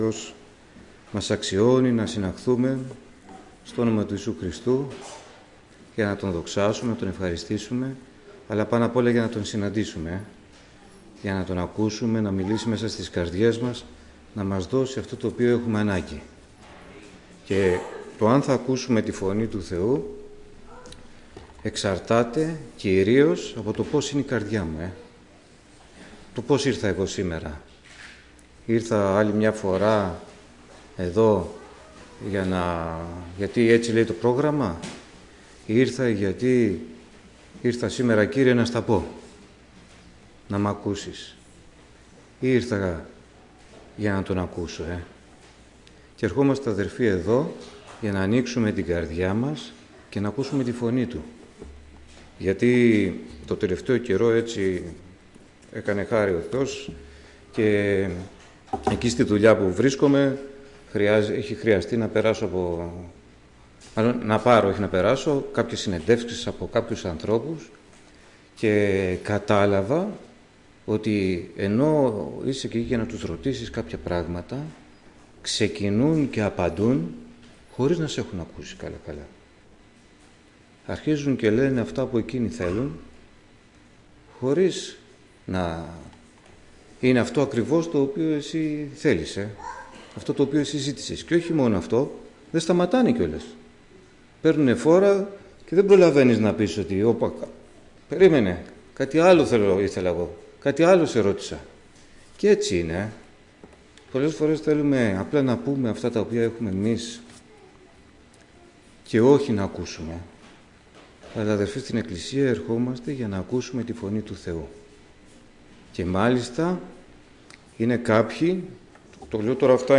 Κήρυγμα Παρασκευής, από Γένεση κβ'[22] 1-13